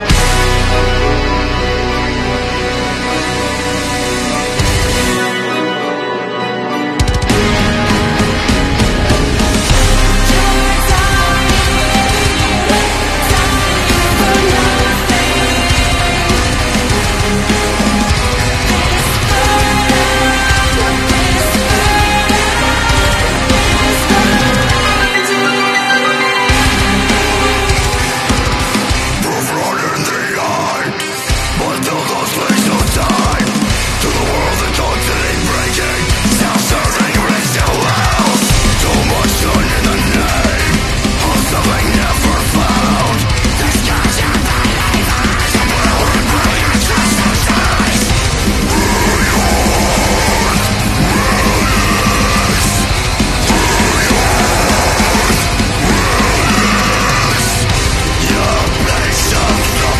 deathcore